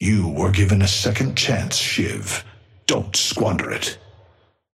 Amber Hand voice line - You were given a second chance, Shiv. Don't squander it.
Patron_male_ally_shiv_start_01.mp3